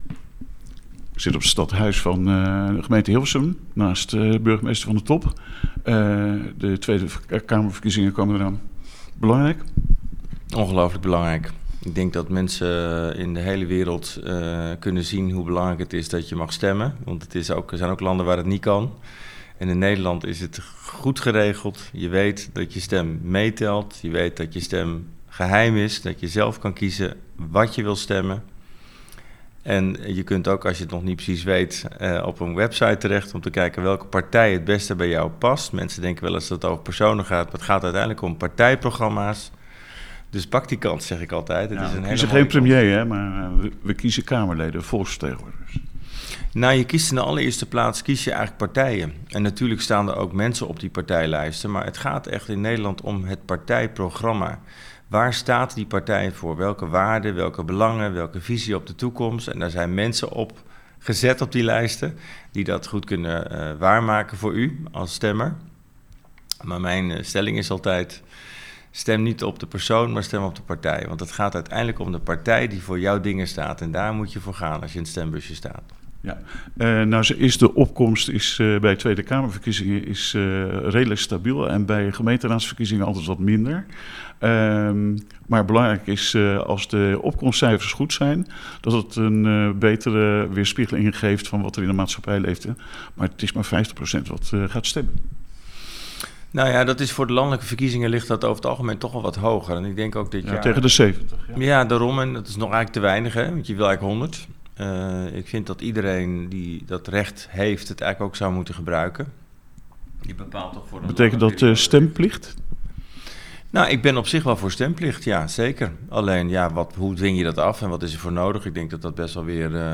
Luister hier naar het interview met de Hilversumse burgemeester Gerhard van den Top: